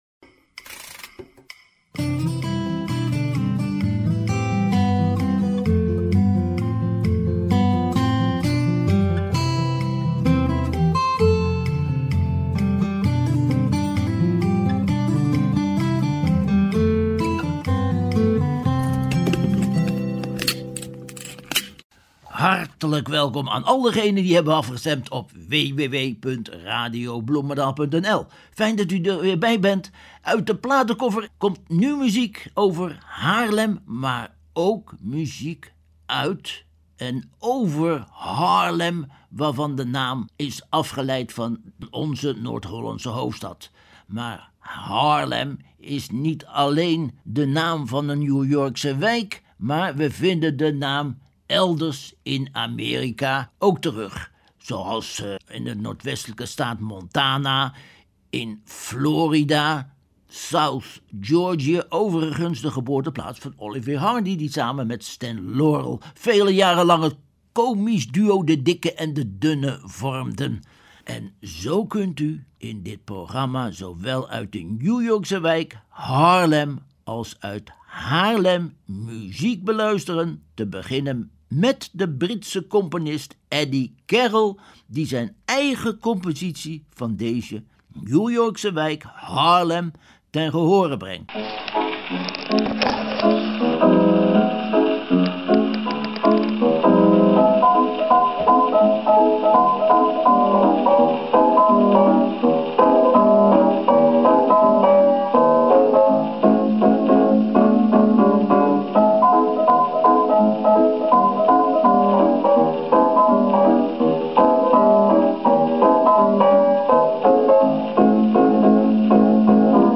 Natuurlijk ontbreken de klokken en de Damiaatjes niet. En er komt ook nog een draaiorgel langs.